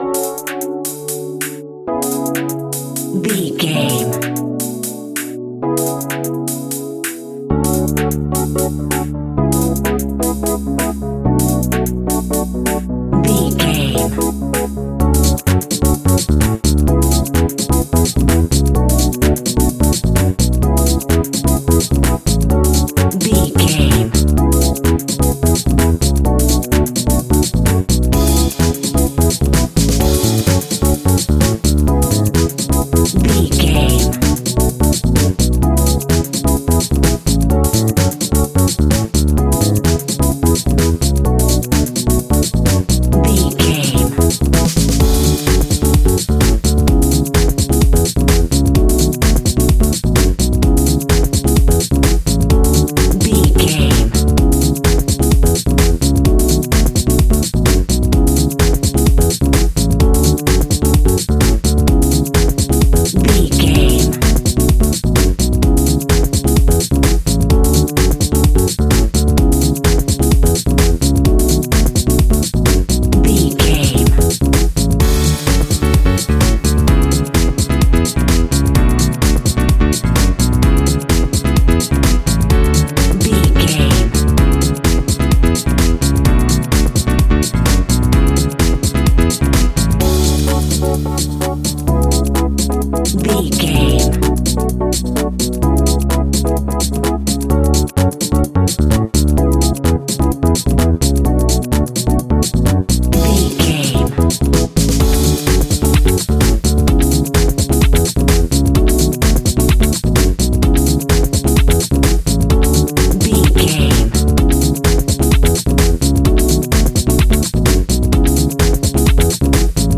Ionian/Major
groovy
uplifting
bouncy
cheerful/happy
electric guitar
horns
bass guitar
drums
disco
synth
upbeat
instrumentals